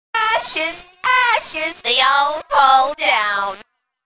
ashes ashes Meme Sound Effect